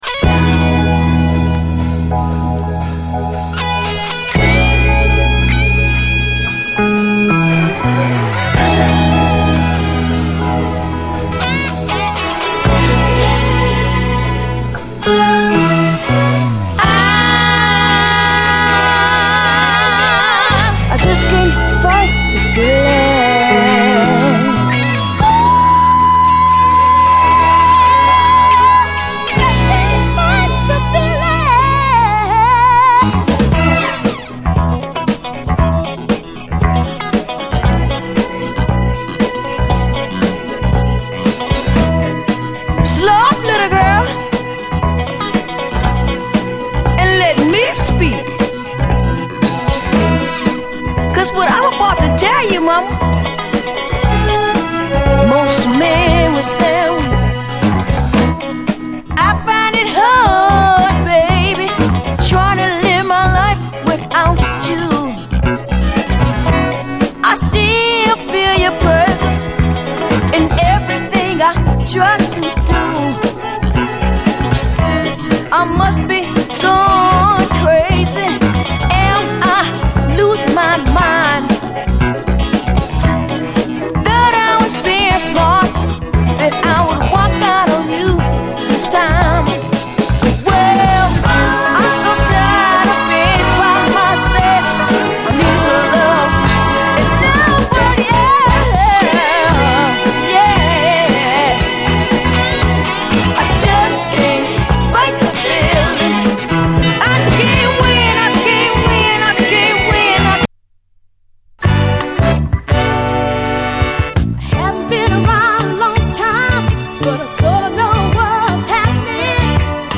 SOUL, 70's～ SOUL
ヤングな5人組兄弟ソウル・グループ！ドラマティックなイントロで始まる、青春ミッド・モダン・ソウル